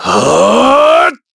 Shakmeh-Vox_Casting3_kr.wav